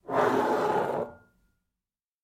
Звуки стеклянной бутылки
Шуршание бутылки скользящей по столу